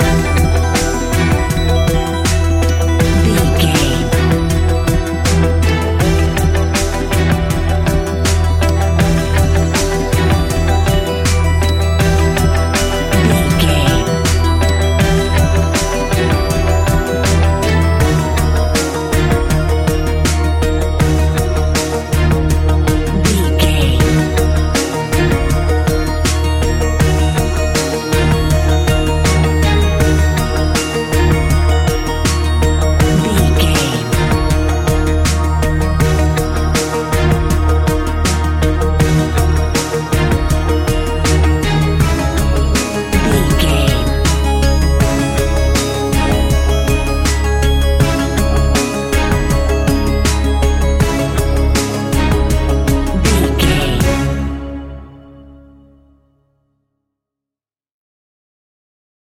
Ionian/Major
C♯
techno
trance
synths
synthwave
instrumentals